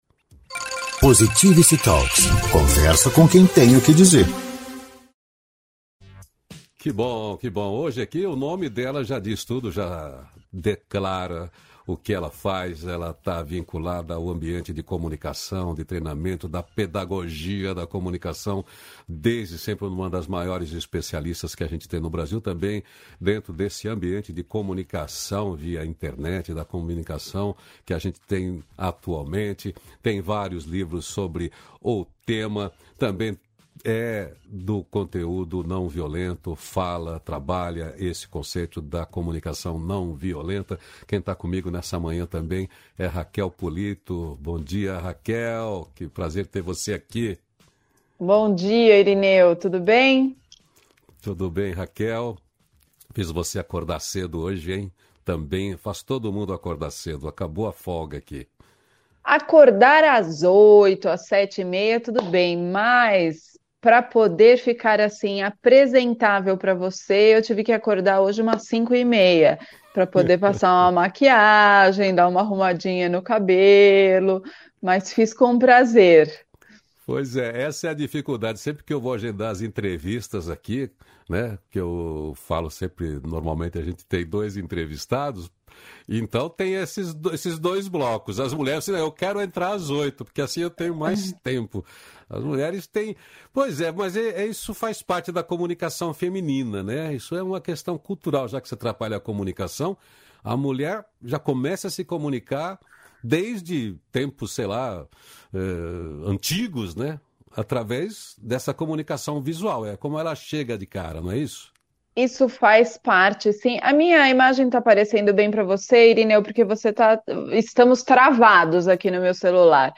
317-feliz-dia-novo-entrevista.mp3